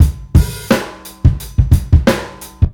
Breakbeat fave 4 87bpm.wav